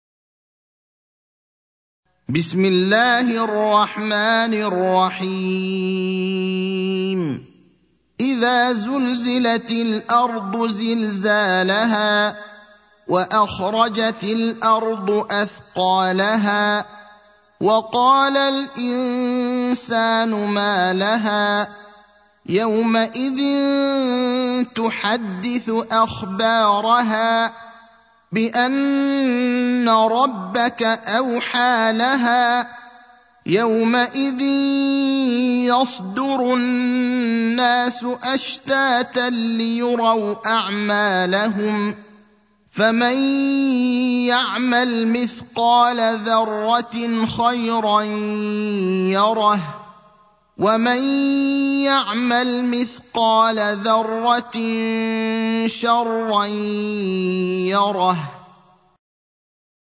المكان: المسجد النبوي الشيخ: فضيلة الشيخ إبراهيم الأخضر فضيلة الشيخ إبراهيم الأخضر الزلزلة (99)